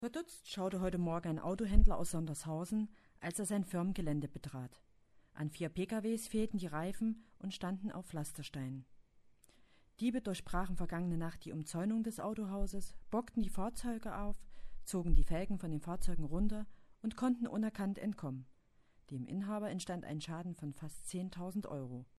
Hier gibt es eine Meldung auf die Ohren